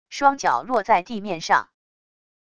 双脚落在地面上wav音频